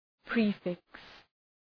Προφορά
{‘pri:fıks} (Ουσιαστικό) ● πρόθεμα ● τίτλος προ του ονόματος